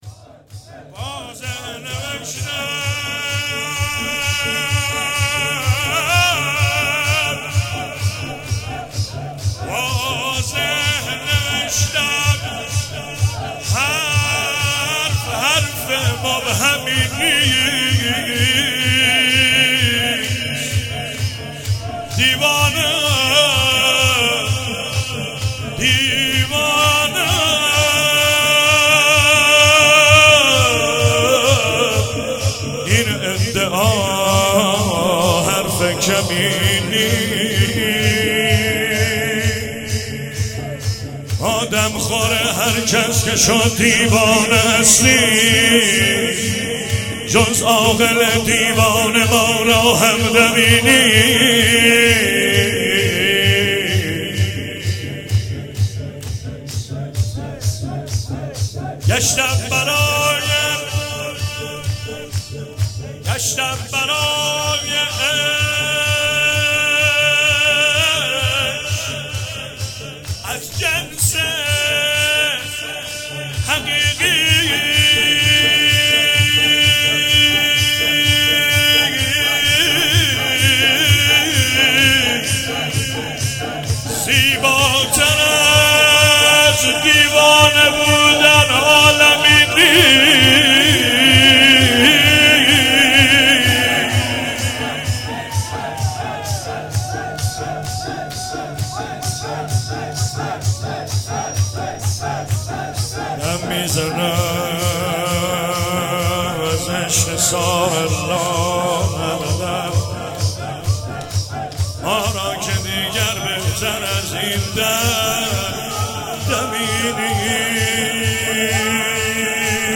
شب دوم محرم95